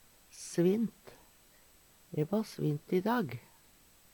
svint - Numedalsmål (en-US)